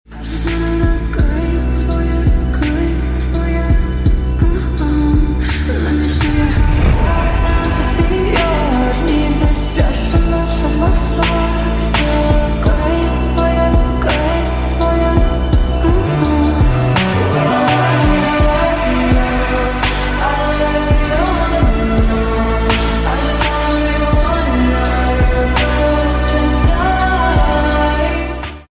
Pure power of the A320 sound effects free download